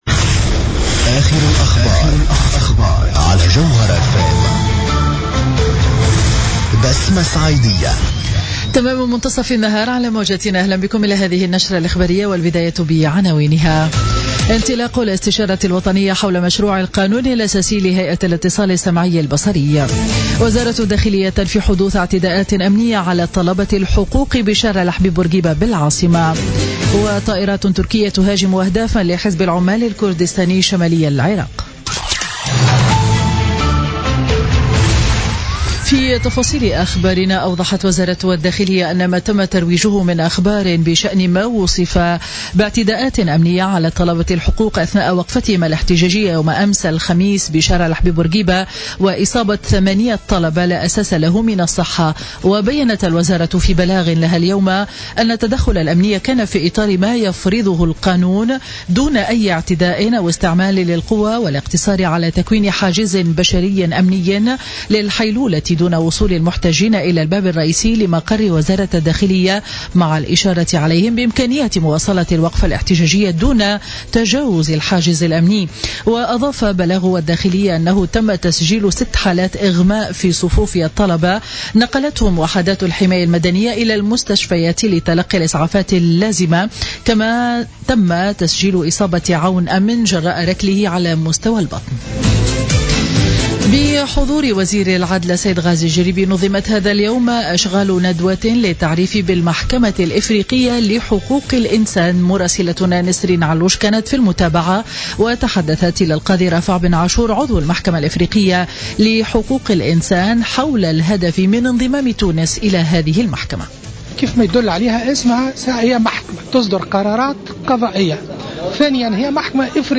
نشرة أخبار منتصف النهار ليوم الجمعة 14 أفريل 2017